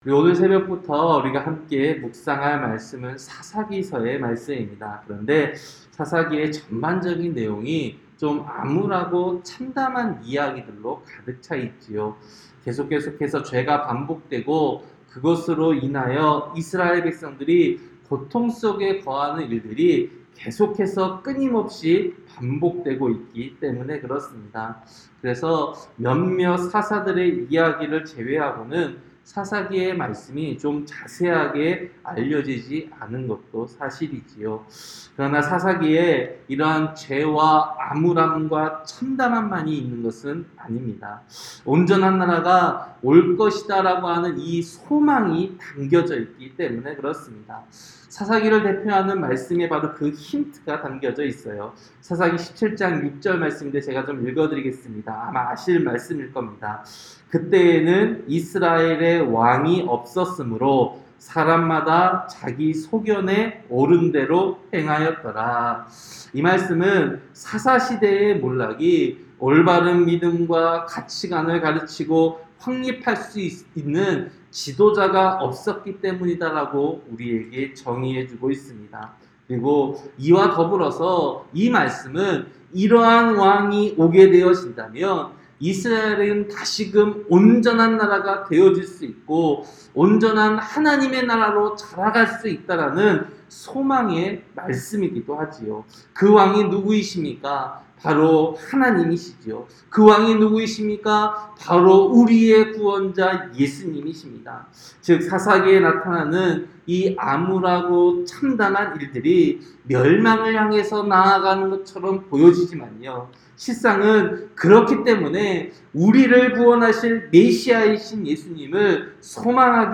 새벽설교-사사기 1장